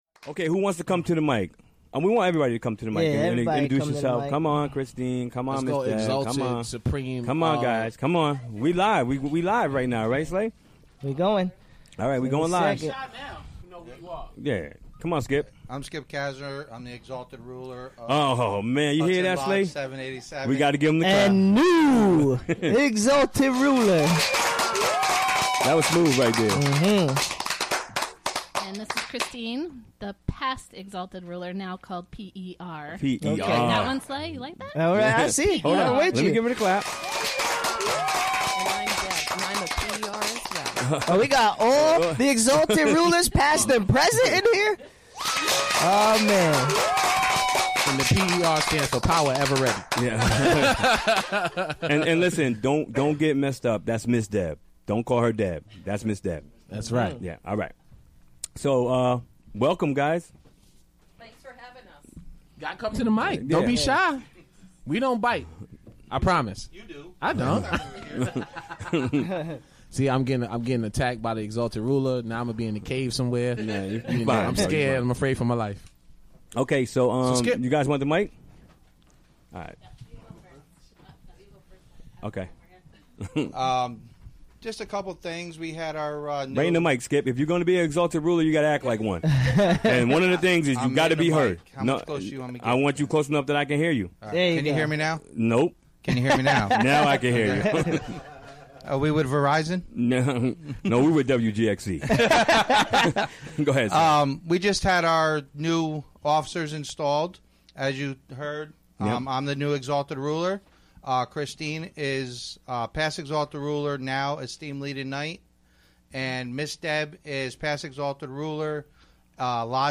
Recorded live during the WGXC Afternoon Show Wed., Apr. 12, 2017.